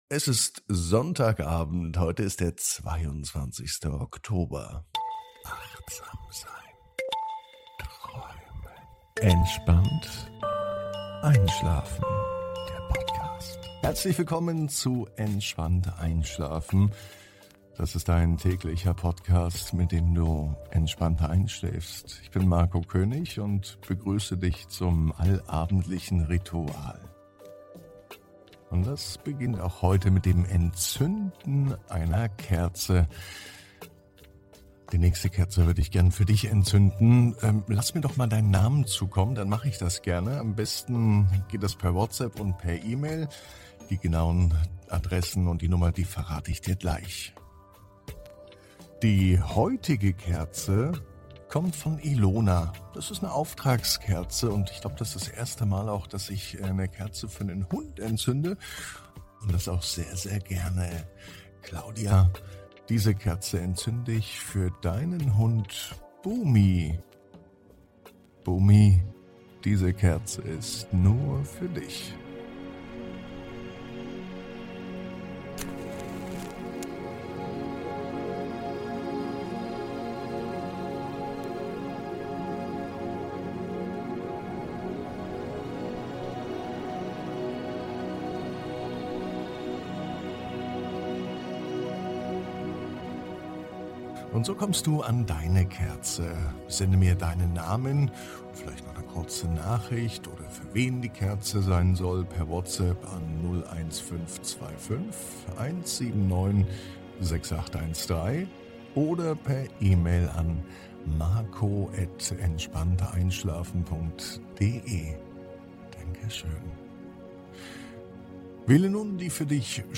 #471 Mika und das Longboard (Wdh) ~ Ab ins Bett - Die tägliche Gute-Nacht-Geschichte Podcast